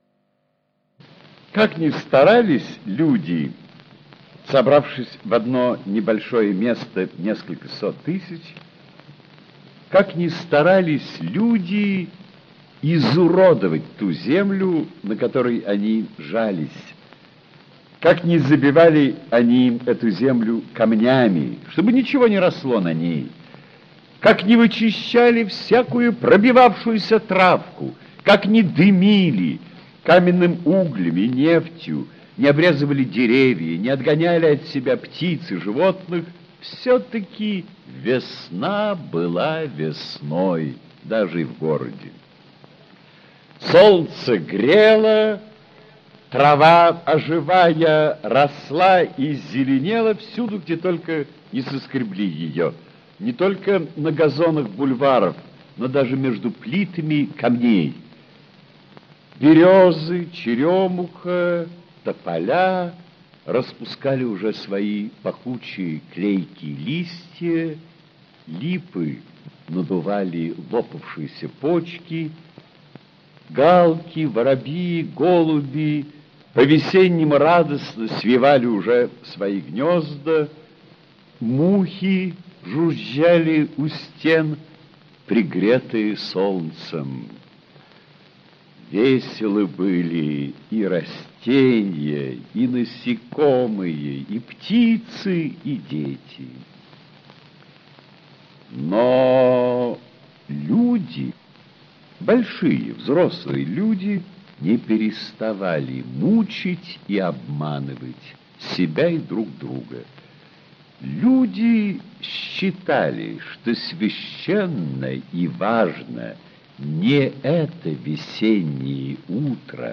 Аудиокнига Воскресение. Аудиоспектакль | Библиотека аудиокниг
Aудиокнига Воскресение. Аудиоспектакль Автор Лев Толстой Читает аудиокнигу Ольга Книппер-Чехова.